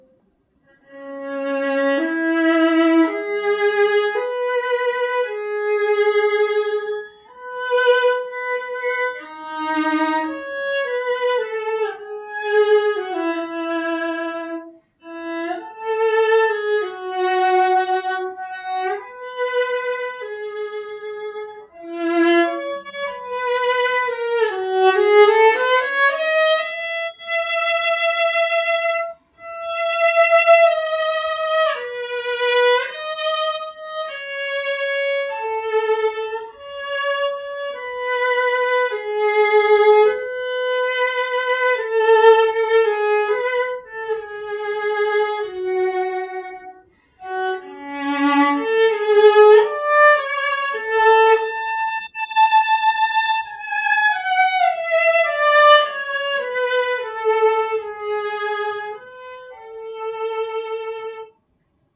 The resulting sound is dark, well balanced, even and with a strong projection.